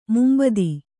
♪ mumbadi